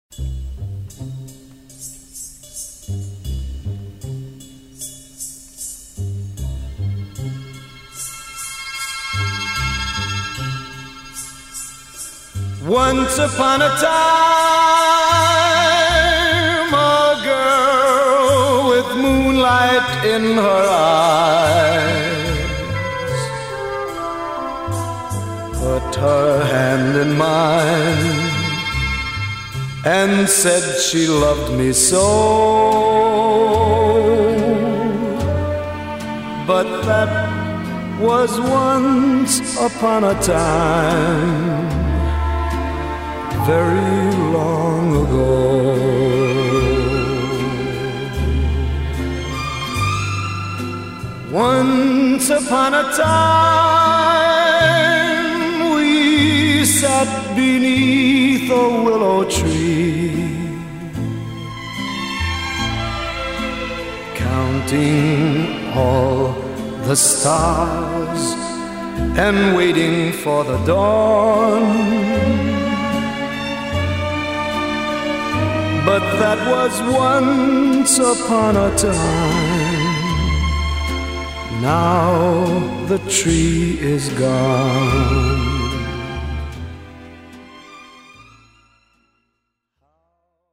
Жанр: R&B • Soul • Blues • Jazz